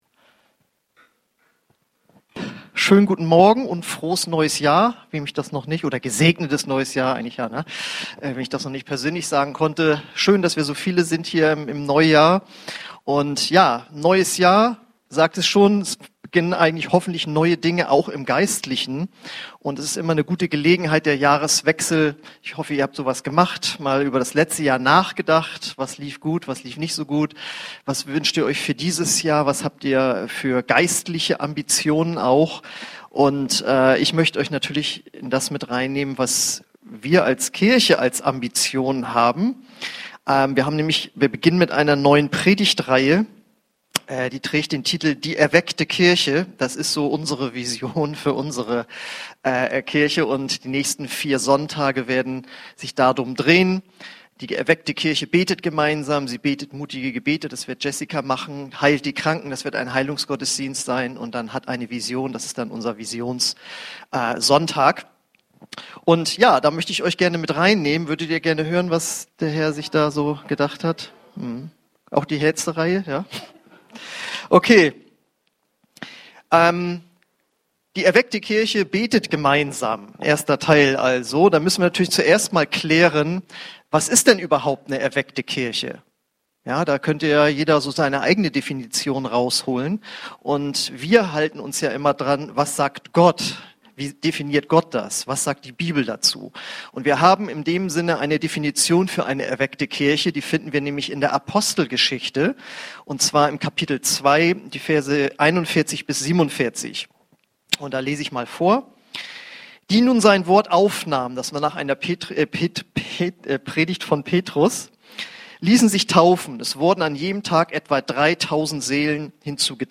Eine predigt aus der predigtreihe "Die erweckte Kirche...."